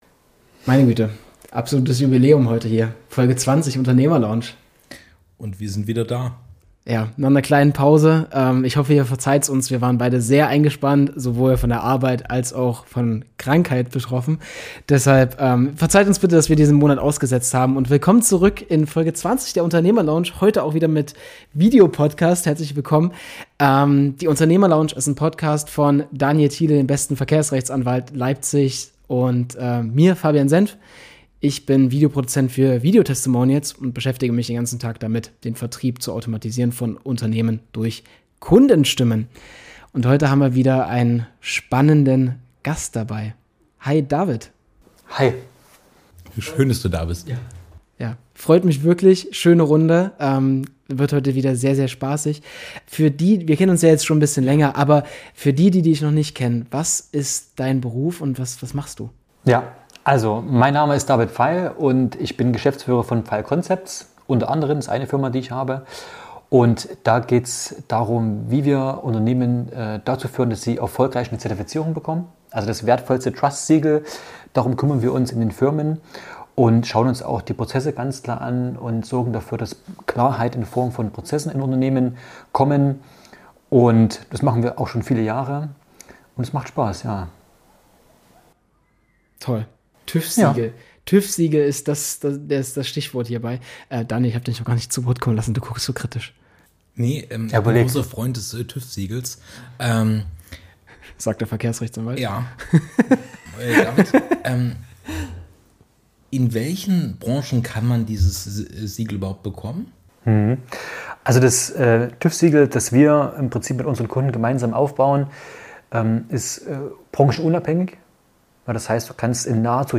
Es wird detailliert auf die Prozesse und Standards eingegangen, die für eine erfolgreiche Zertifizierung erforderlich sind und wie diese zur Effizienzsteigerung in Unternehmen beitragen können. Zudem werden humorvolle Anekdoten und diverse interessante Einblicke in das internationale Geschäftsumfeld, wie in Dubai, besprochen. Die Episode endet in einer humorvollen, teils satirischen Tonalität, die auch Themen wie städtische Partnerschaften und möglicherweise gekaufte Siegel streift.